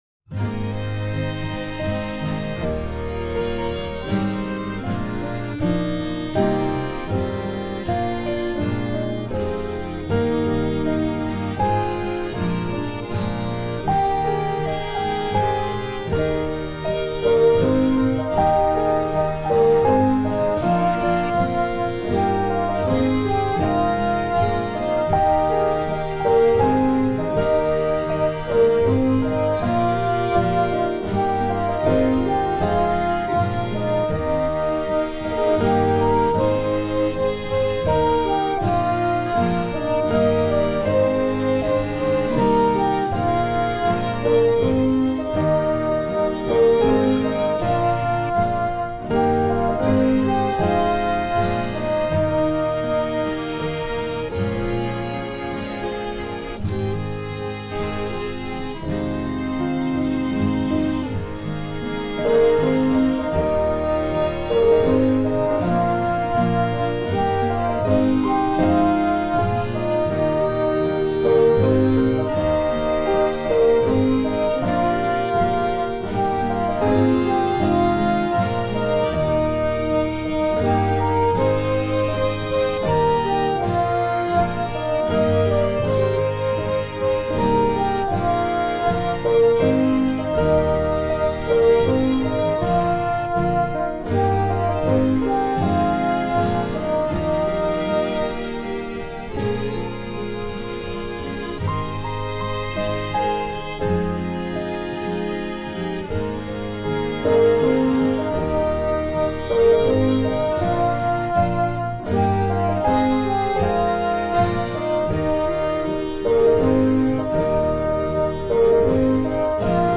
It is a vaguely Celtic style and is an attractive tune.